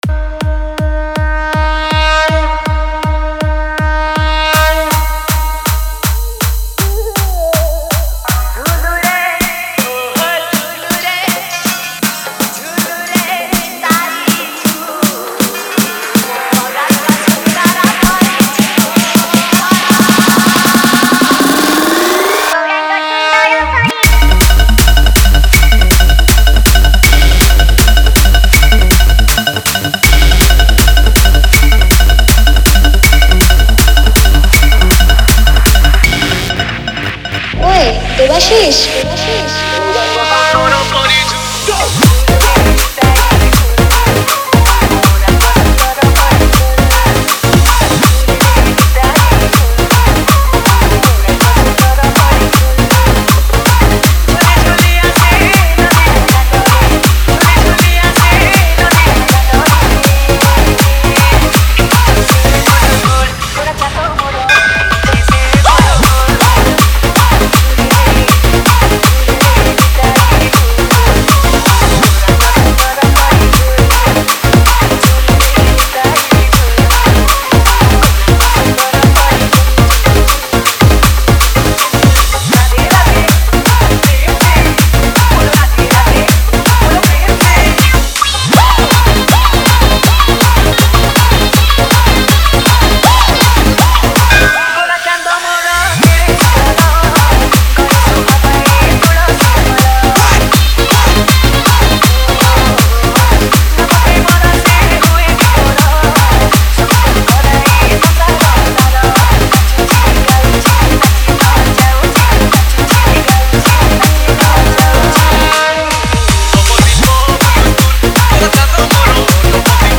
Bhajan Dj Song Collection 2022